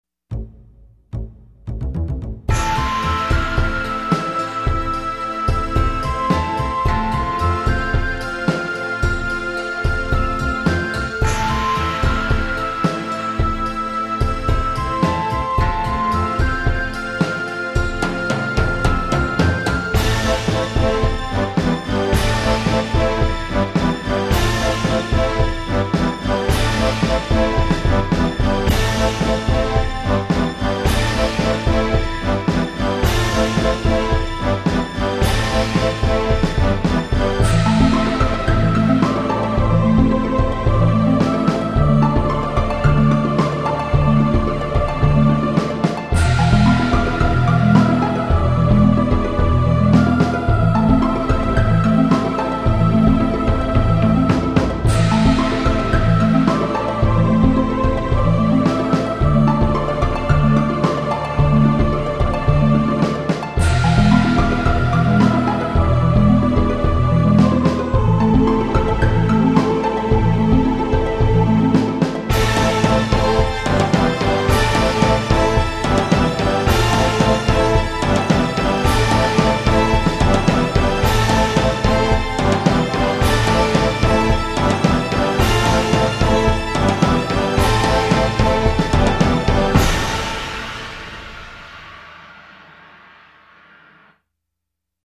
[MP3] 사운드데모/오케스트라